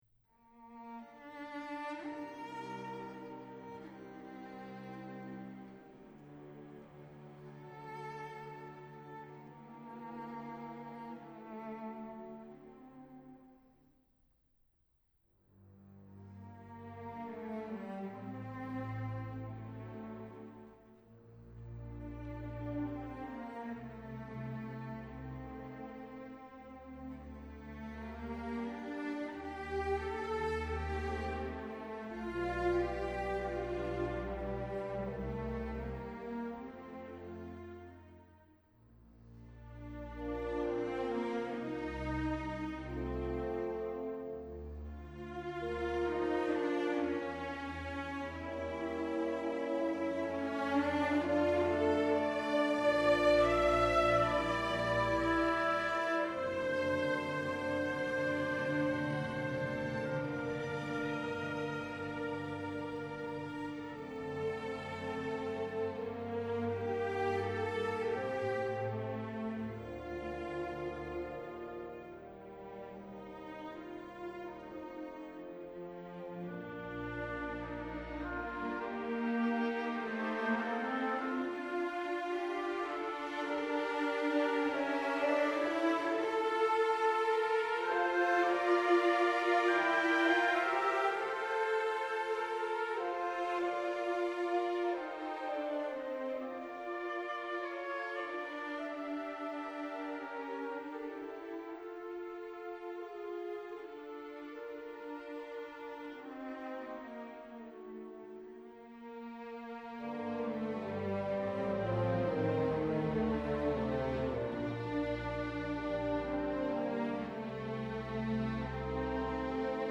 oeuvres orchestrales
magnifiquement interprétées par d’excellents orchestres anglais